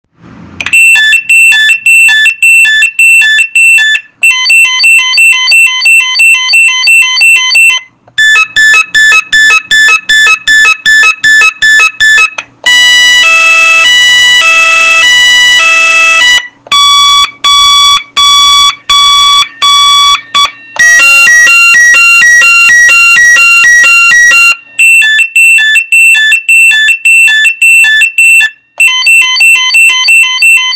Sirene Eletrônica com Sinalizador Visual
• Potência sonora: 100 dB (a 1 metro)